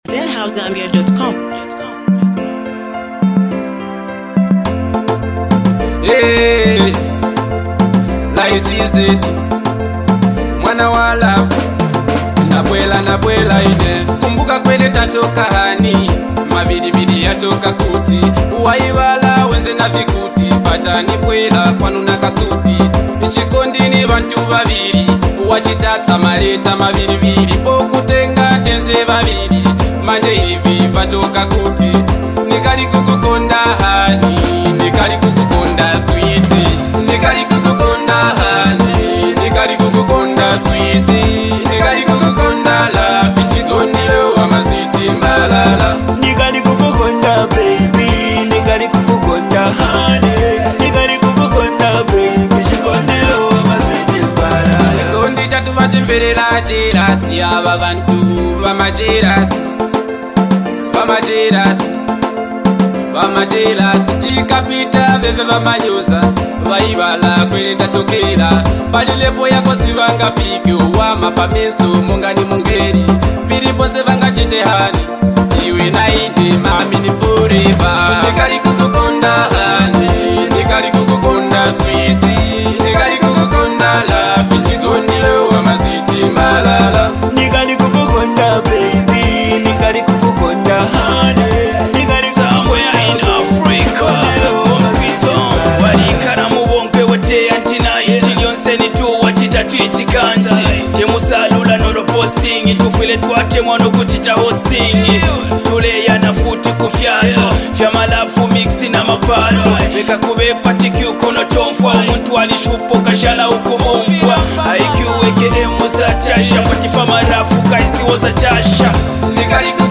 soulful track